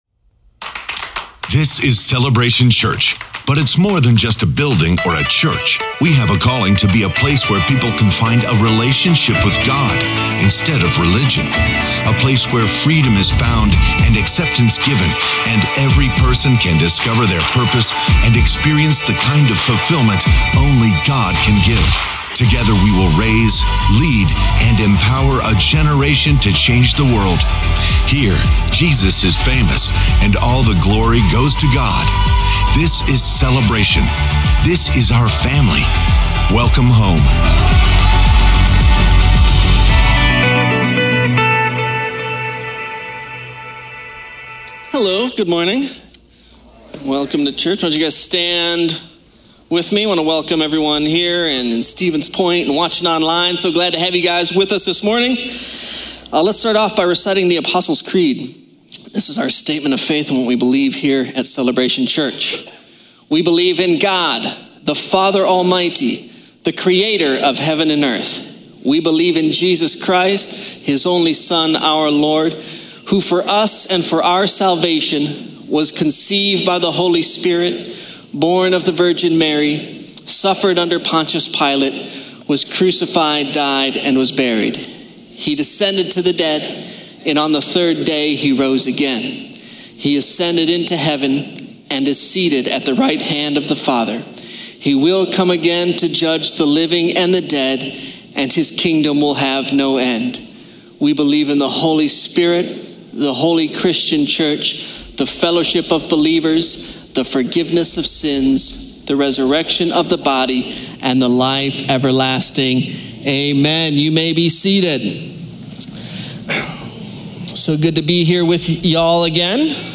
preaches a message to our Sunday morning services.